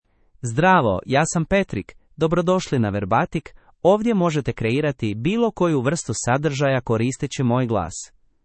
MaleCroatian (Croatia)
PatrickMale Croatian AI voice
Voice sample
Listen to Patrick's male Croatian voice.
Male
Patrick delivers clear pronunciation with authentic Croatia Croatian intonation, making your content sound professionally produced.